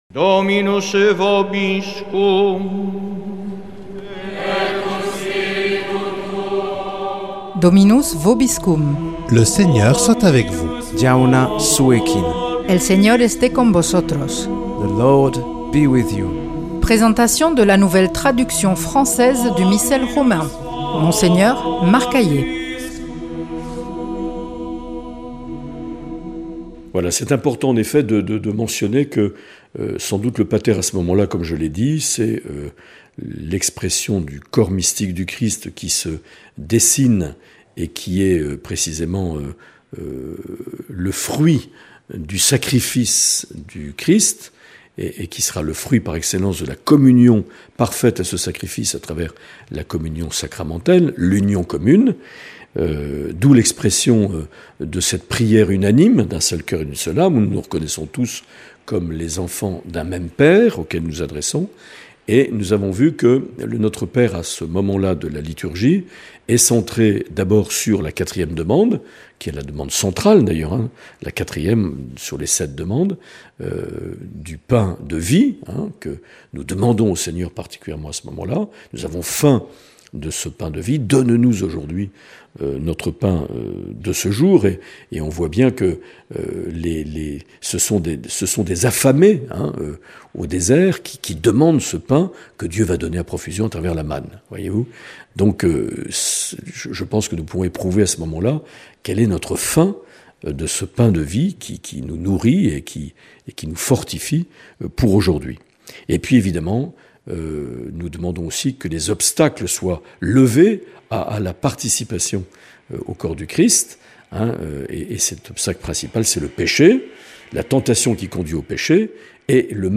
Présentation de la nouvelle traduction française du Missel Romain par Mgr Marc Aillet